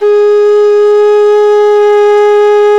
SULING G#3.wav